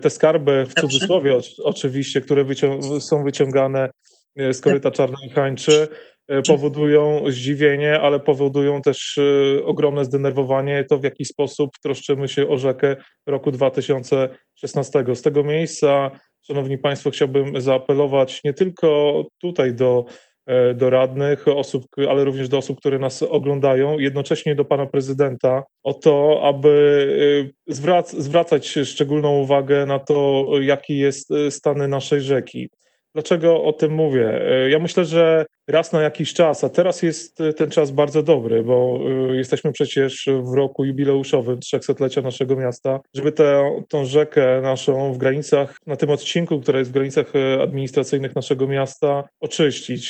O sprawie mówił na ostatniej sesji Kamil Klimek, miejski radny i pracownik OSiRu. Apelował o dbałość o rzekę.